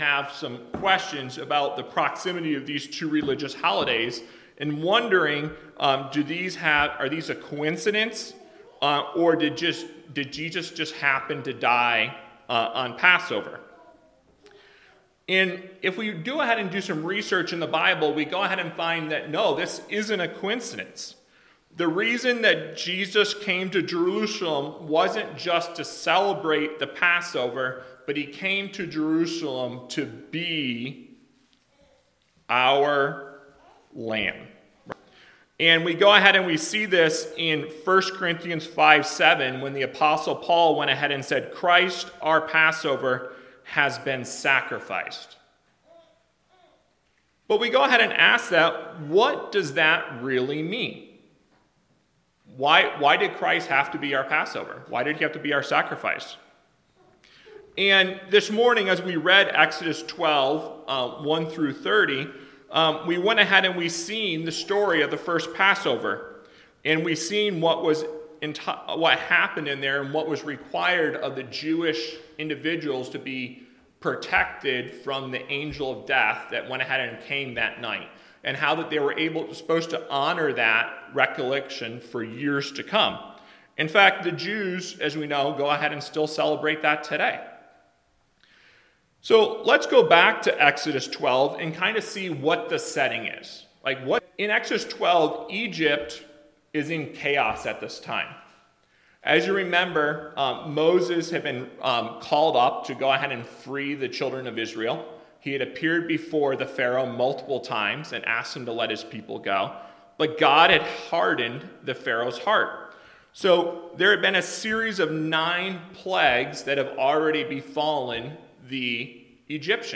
Worship Service – September 14, 2025 « Franklin Hill Presbyterian Church